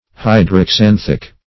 Search Result for " hydroxanthic" : The Collaborative International Dictionary of English v.0.48: Hydroxanthic \Hy`dro*xan"thic\, a. [Hydro-, 2 + xanthic.]
hydroxanthic.mp3